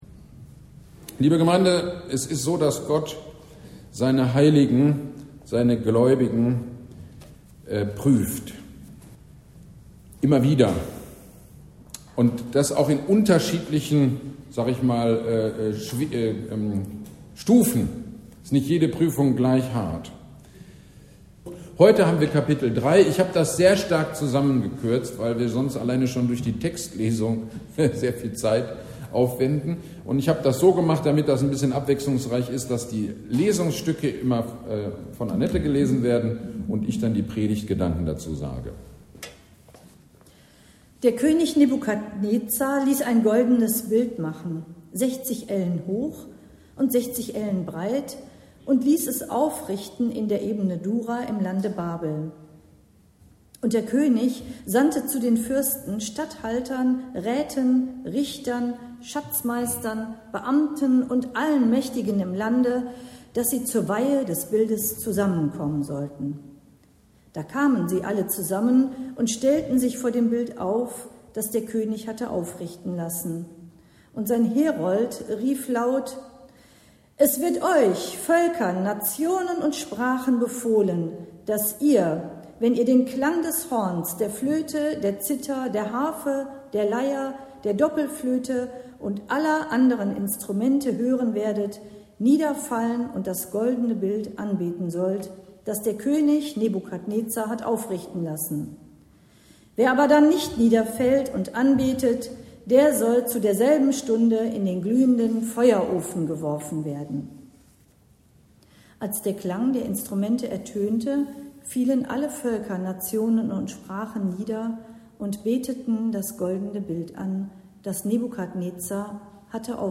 GD am 08.09.24 Predigt zu Daniel 3